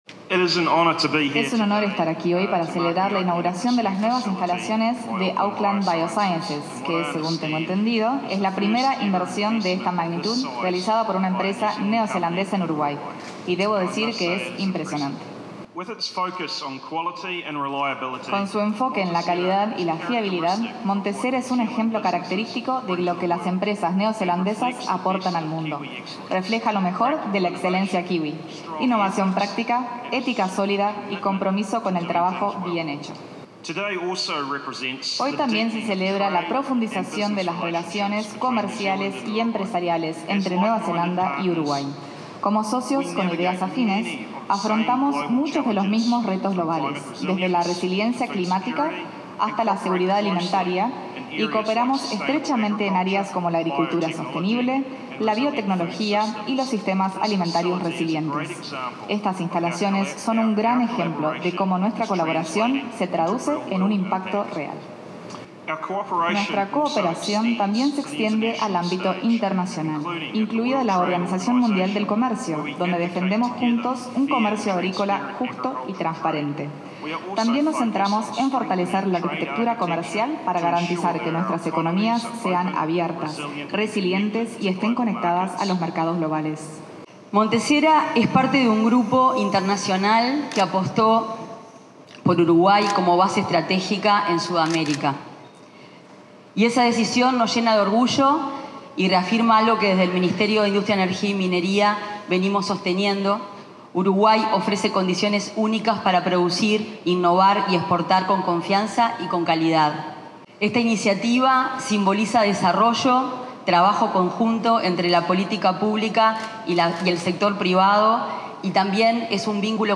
Palabras de autoridades en inauguración de planta de MonteSera
El ministro de Bioseguridad y Seguridad Alimentaria de Nueva Zelanda, Hon Andrew Hoggard, y la ministra de Industria, Fernanda Cardona, se expresaron
Palabras de autoridades en inauguración de planta de MonteSera 23/10/2025 Compartir Facebook X Copiar enlace WhatsApp LinkedIn El ministro de Bioseguridad y Seguridad Alimentaria de Nueva Zelanda, Hon Andrew Hoggard, y la ministra de Industria, Fernanda Cardona, se expresaron durante la inauguración de una planta de MonteSera, ubicada en el Parque de las Ciencias, en Canelones.